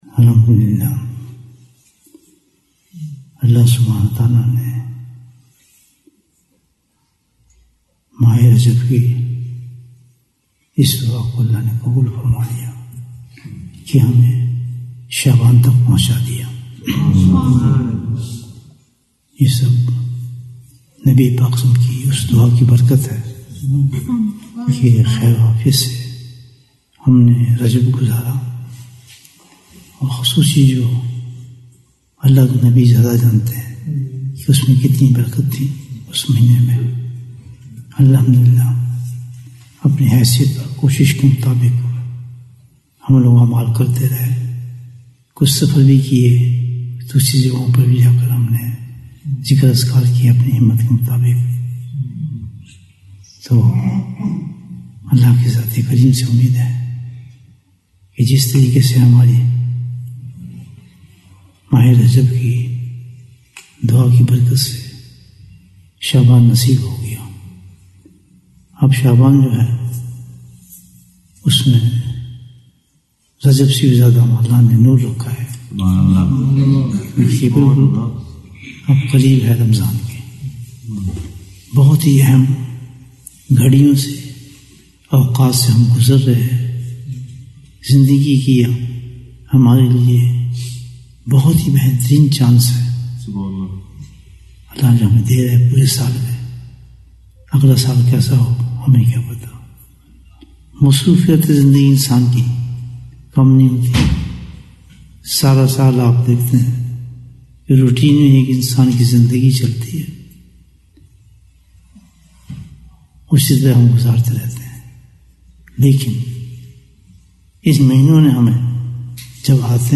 ذکر سے پہلے بیان 1714 minutes1st February, 2025